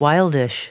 Prononciation du mot : wildish
wildish.wav